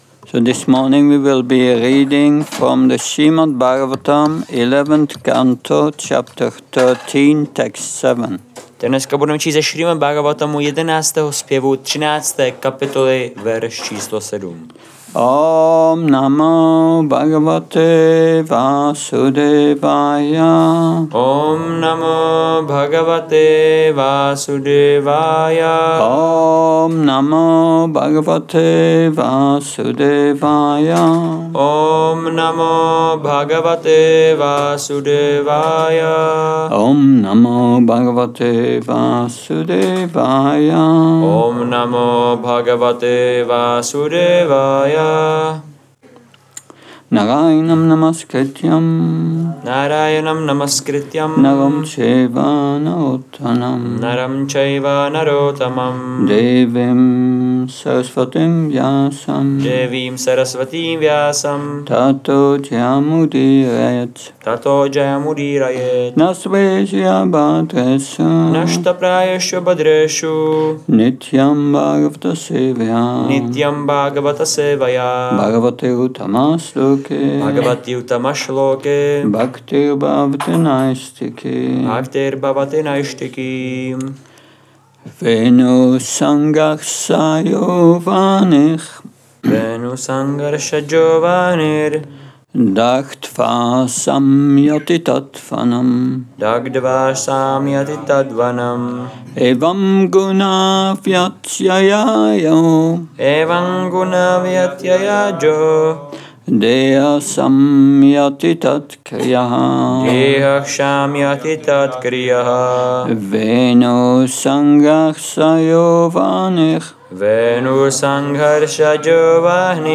Přednáška SB-11.13.7 – Šrí Šrí Nitái Navadvípačandra mandir